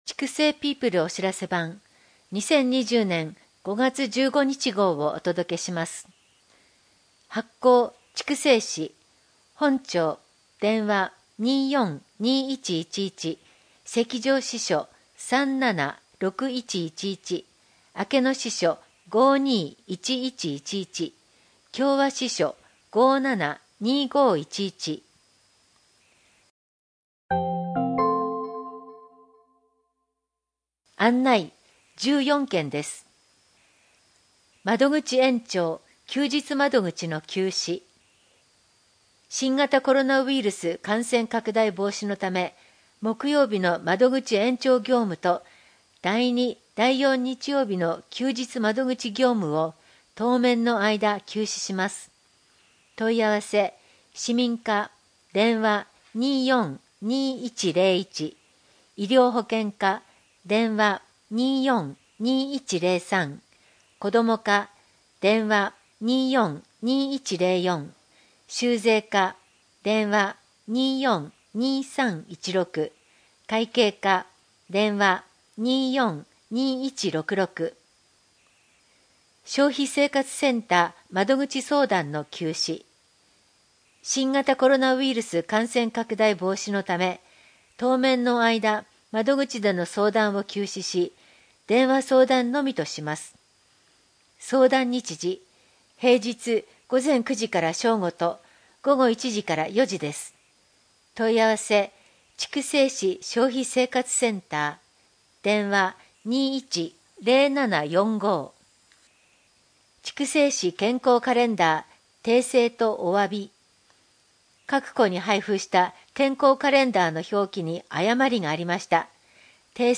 声の広報は、朗読ボランティア「野ばらの会」様のご協力により、目の不自由な人や高齢者など、広報紙を読むことが困難な人のために「声の広報筑西People」としてお届けしています。